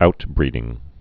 (outbrēdĭng)